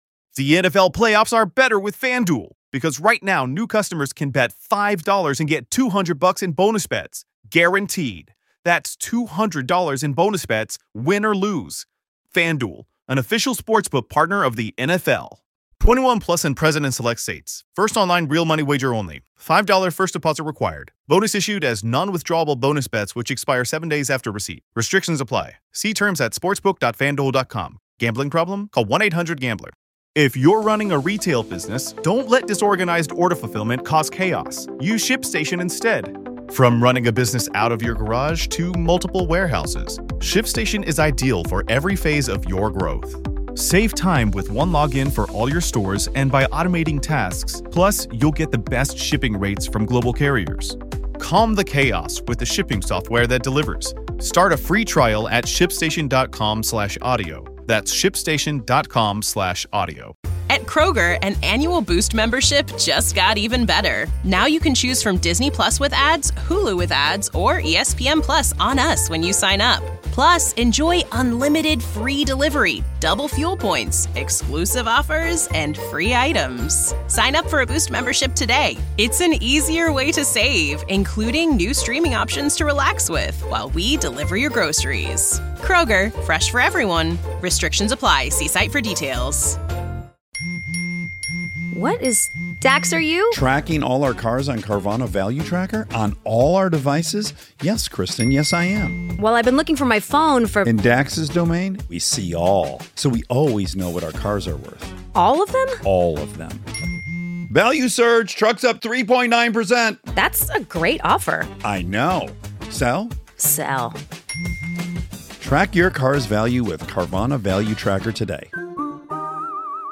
Shoot Interviews